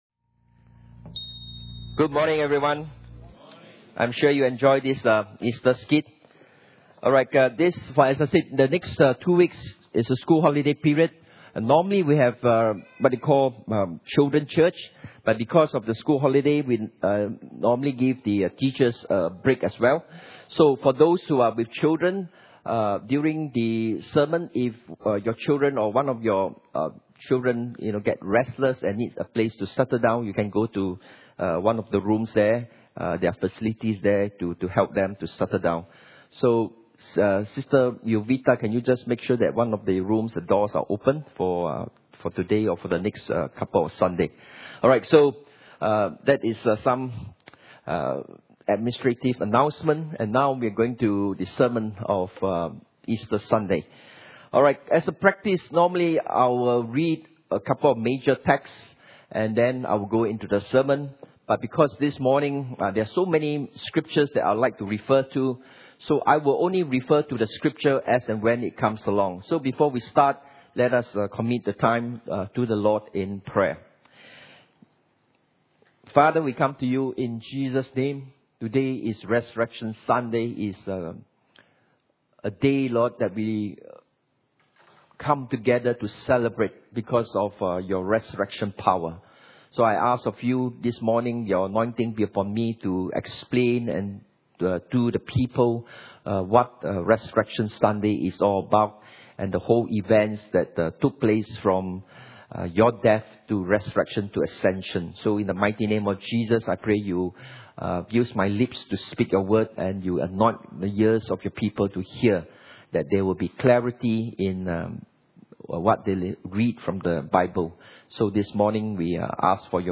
Easter Sunday Message – Tracking Jesus’ Death, Resurrection and Ascension
Inhouse Service Type: Sunday Morning « Faith Tracking Jesus’ Death